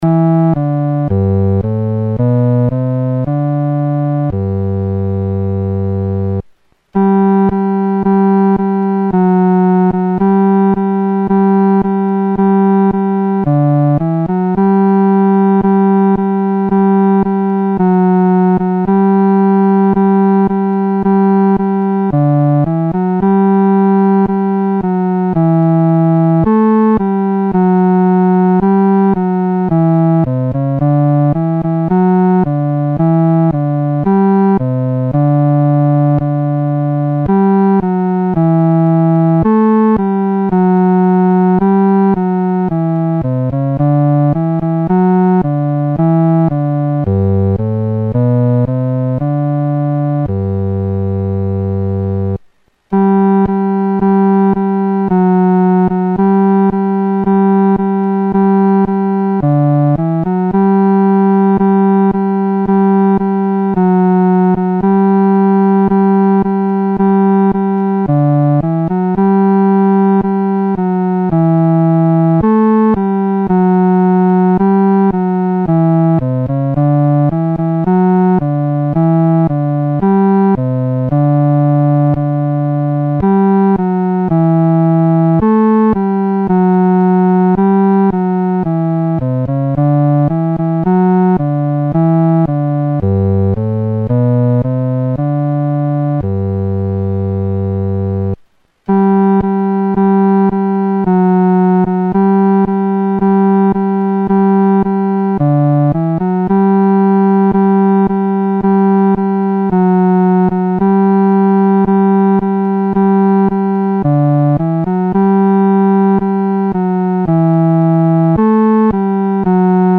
独奏（第四声）
Traditional French Carol,1855